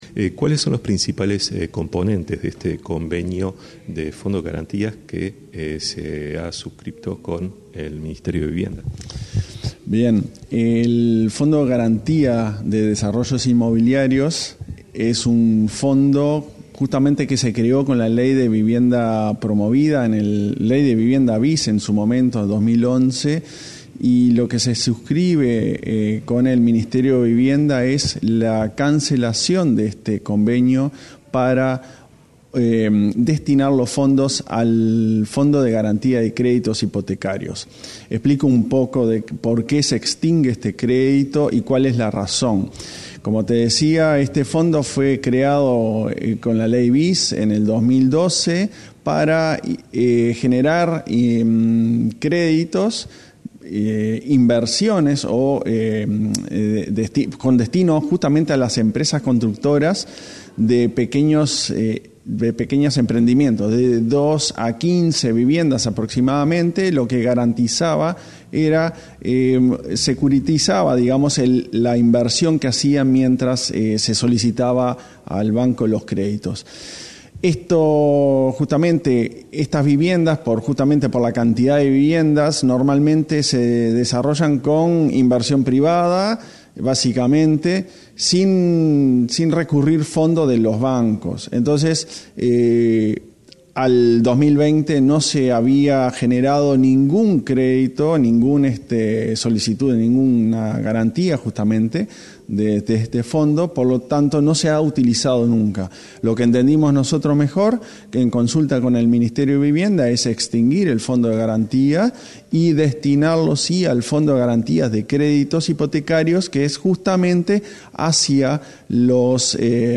Entrevista al presidente de la ANV, Klaus Mill
Entrevista al presidente de la ANV, Klaus Mill 09/06/2023 Compartir Facebook X Copiar enlace WhatsApp LinkedIn La Agencia Nacional de Vivienda (ANV) firmó un convenio con el Ministerio de Vivienda para transferir el saldo del Fondo de Garantía para el Financiamiento de Desarrollos Inmobiliarios al Fondo de Garantía de Créditos Hipotecarios. Sobre el tema, el presidente de la ANV, Klaus Mill, realizó declaraciones a Comunicación Presidencial.